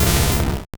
Cri de Sablaireau dans Pokémon Or et Argent.